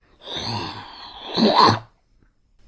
僵尸
描述：我使用我的声音和izotope的vocalsynth2为你做了这个
标签： 生物 僵尸 SFX 怪兽 低吼 可怕
声道立体声